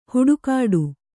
♪ huḍukāḍu